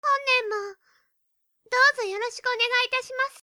/ M｜他分類 / L50 ｜ボイス